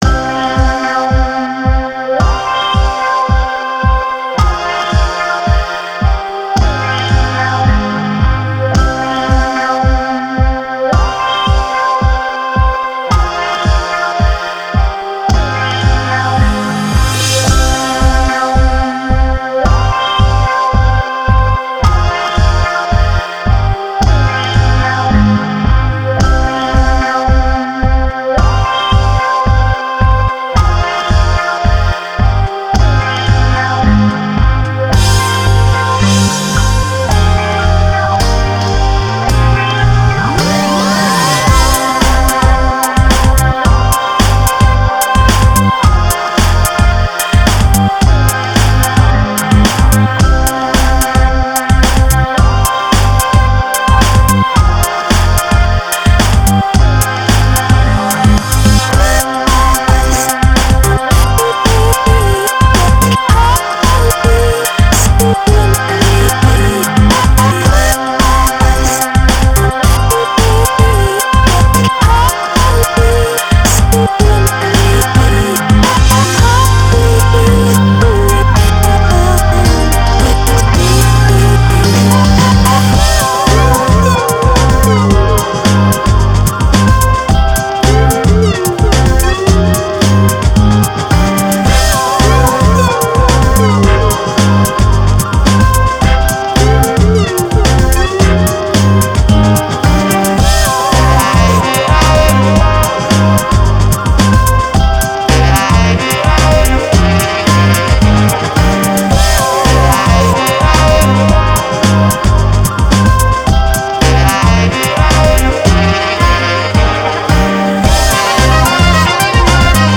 Very relaxing, Keep them coming!